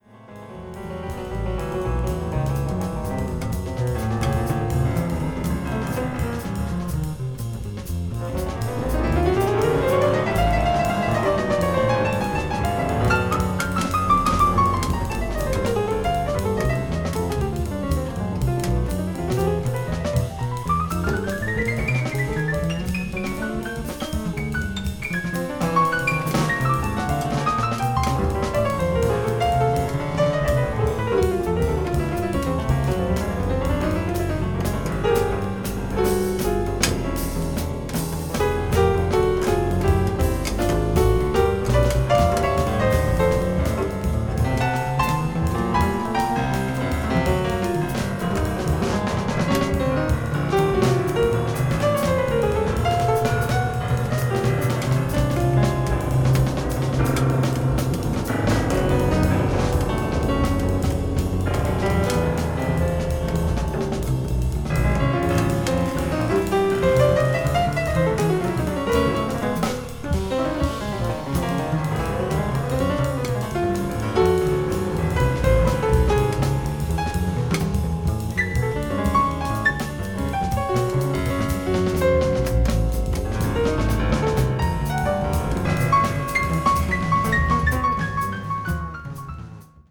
avant-jazz   contemporary jazz   free jazz   post bop